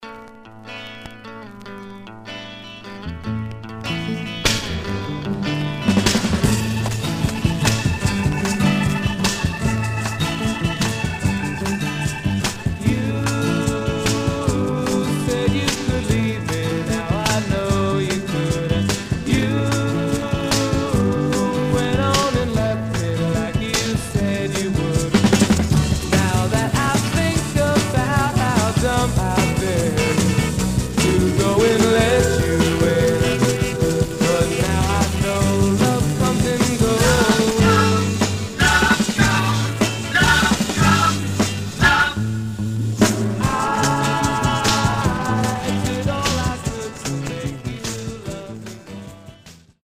Stereo/mono Mono
Garage, 60's Punk